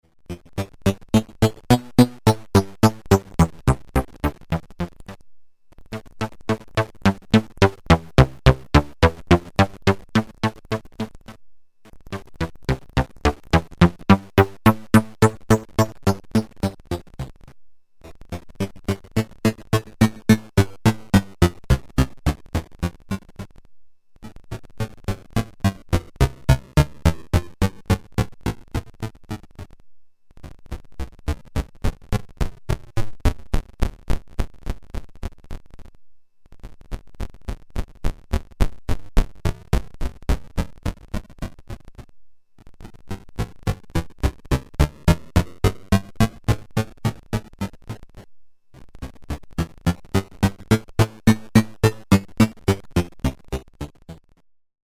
This is an interesting module if you are into distortion, low-fi and all kind of weird effects.
As a sound source, I’m using three A-110 VCOs through an A-108 VCF and then through an A-132-3 VCA. Everything is driven by a sequencer. VCA output is sent into the Bit Cruncer, which is modulated by two slow triangle LFOs.
For each Bit Cruncher function, I’m starting around the highest sampling rate, after ca. 30 seconds we can hear what happens with the lowest sampling rate. The LFO which modulates the function’s parameter is a bit faster, so you should hear a lot of combinations of both modulations:
16. four stafes FIR filter: